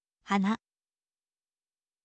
hana, ฮะนะ